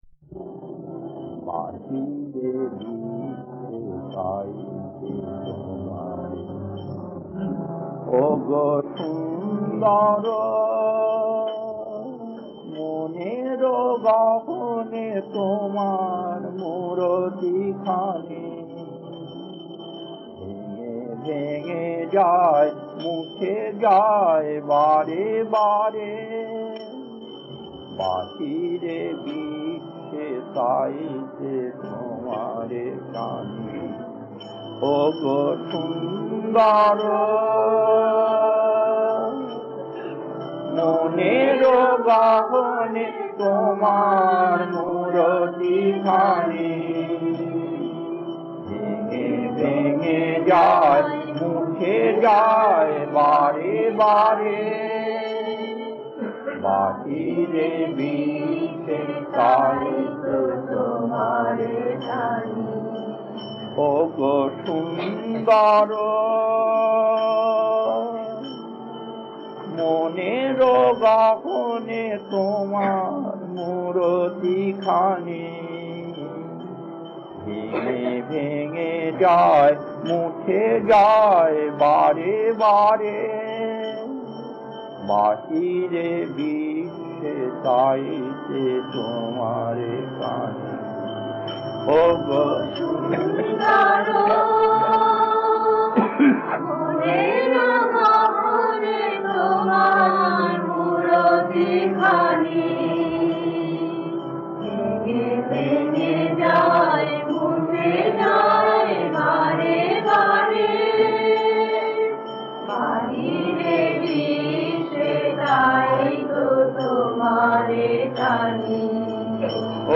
Kirtan A11-1 Madras mid 80's 1.